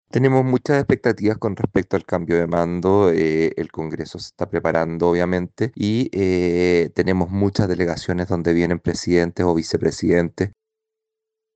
Por su parte, el diputado José Miguel Castro (RN) destacó que su sector tiene altas expectativas por la presencia de numerosas delegaciones internacionales.